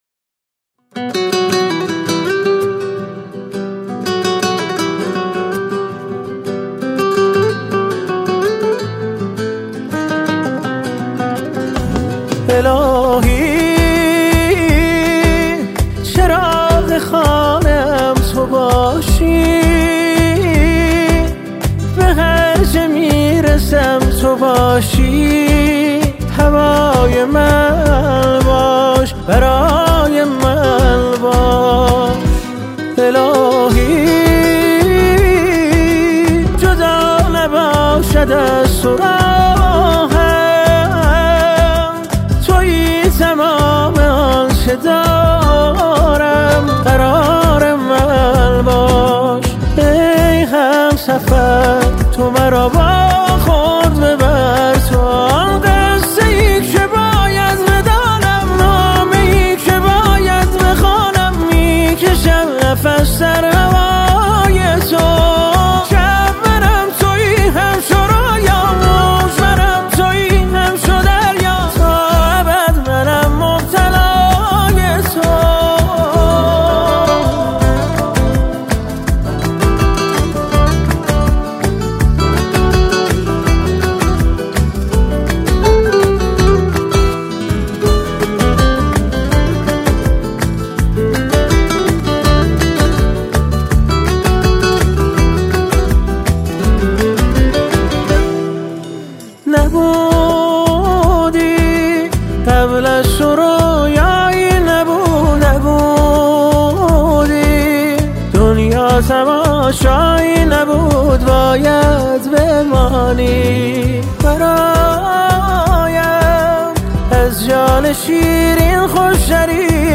اهنگ زیبا و احساسی